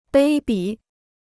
卑鄙 (bēi bǐ): подлый; бессовестный.